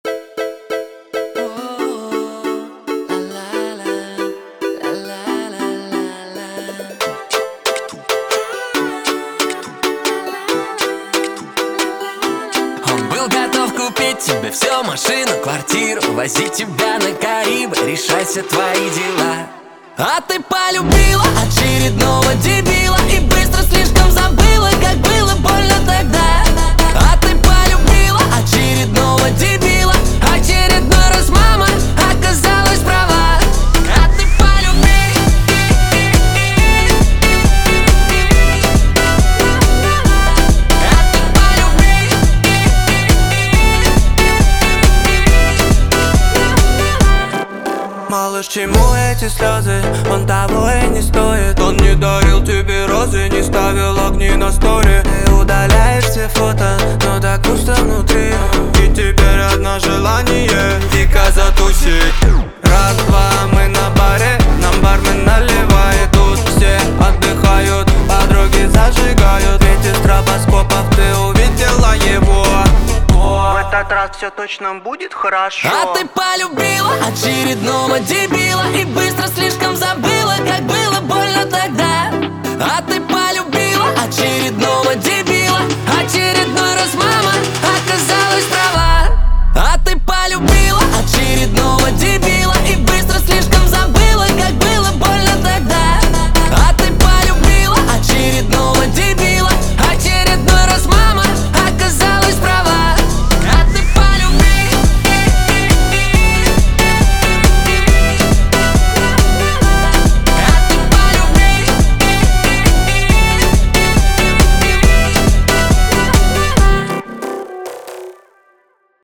Качество: 320 kbps, stereo
Русские поп песни
ремикс